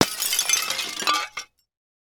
Звук разбитого любящего сердца из-за измены